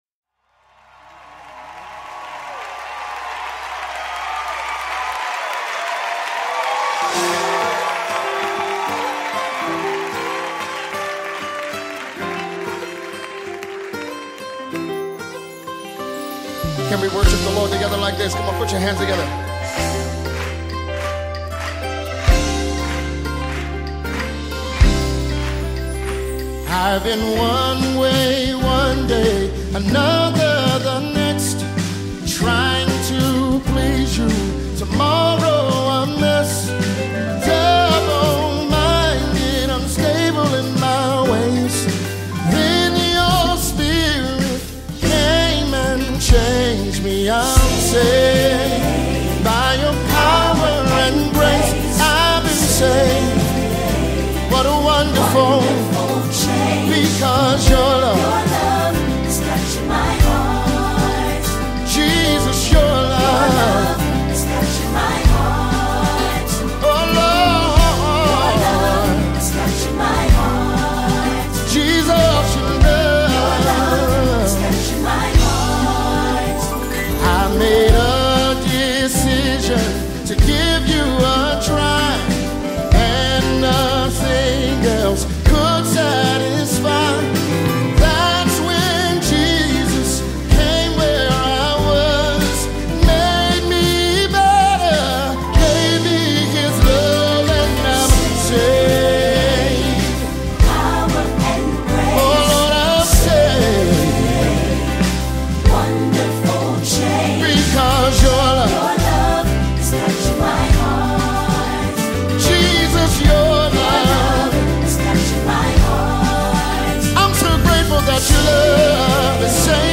Mp3 Gospel Songs
The gifted American gospel singer
offers another impressive and beautiful gospel melody